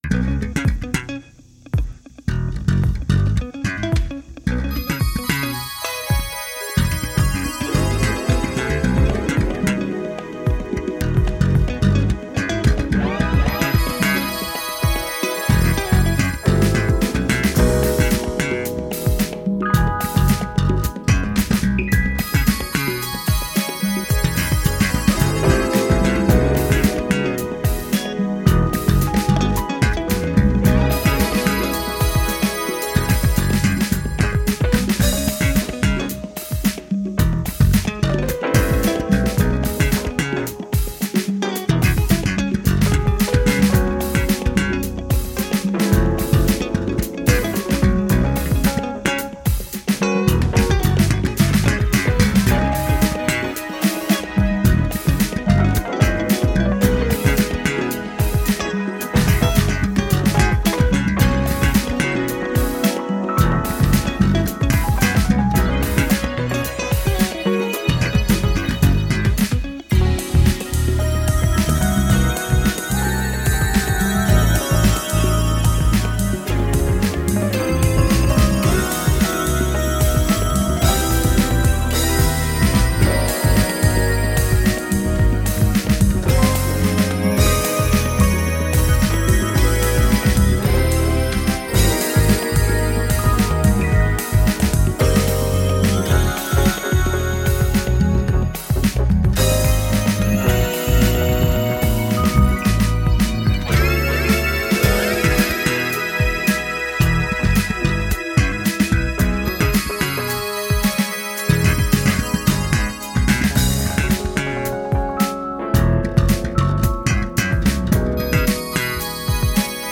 Electric Funk Jazz
Latin Percussion
Guitar
performer: Electric piano, bass guitar, synthesizers